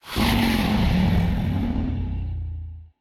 mob / enderdragon / growl4.ogg
growl4.ogg